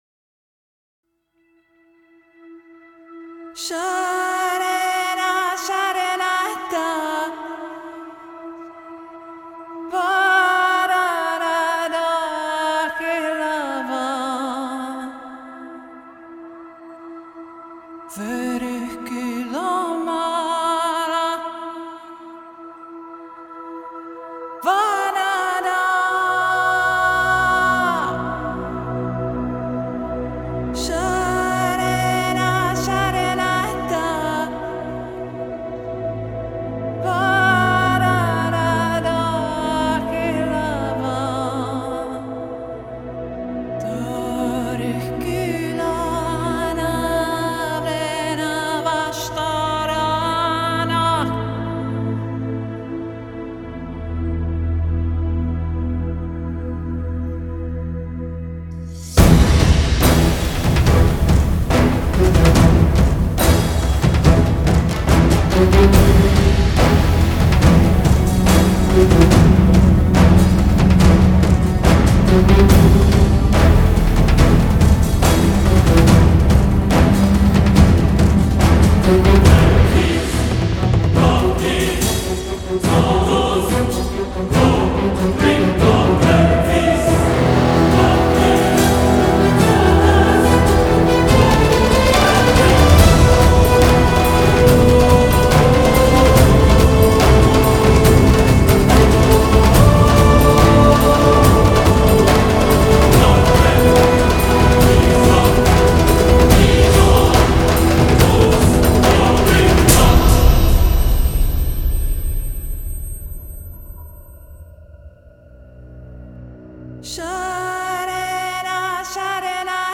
Singer: Instrumental